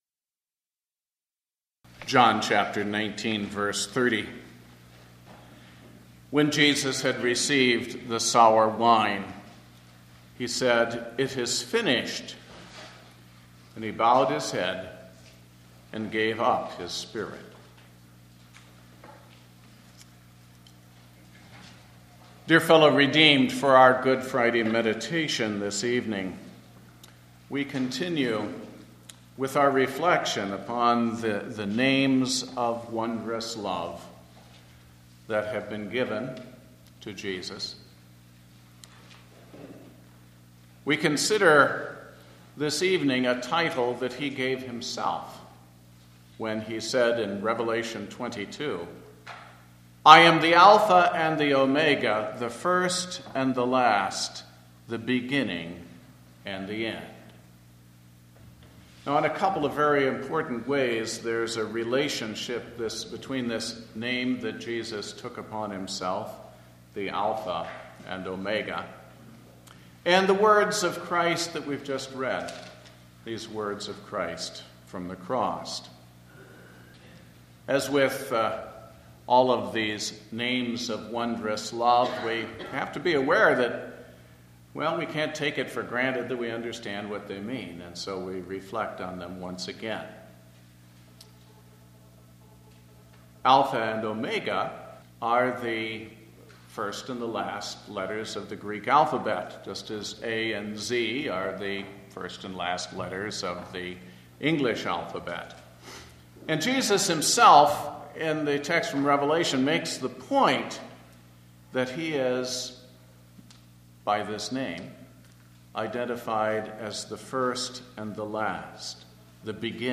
Homily based on John 19:30 Good Friday of Holy Week